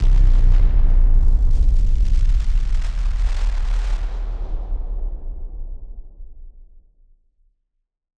BF_SynthBomb_D-02.wav